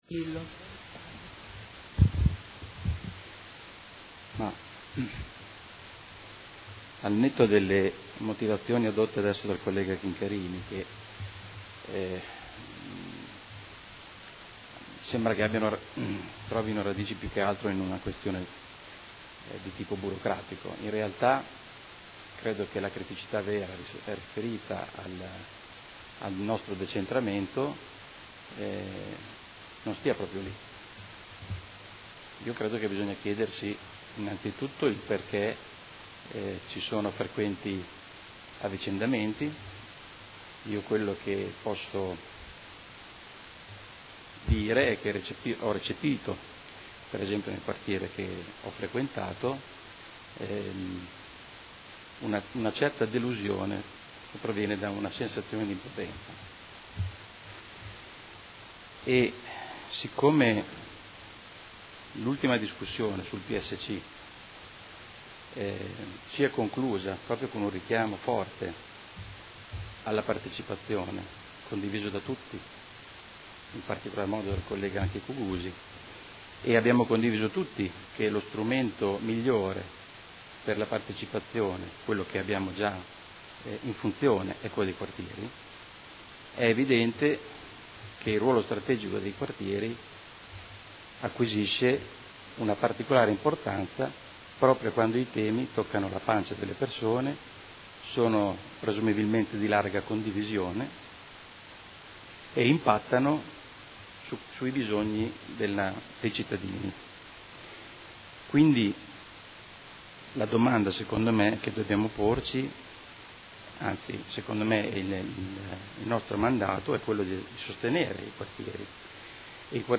Seduta del 22 ottobre. Proposta di deliberazione: Consiglieri dimissionari dei Quartieri 1 e 3 – nomine nuovi componenti. Dibattito